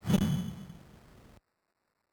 Retro Swooosh 16.wav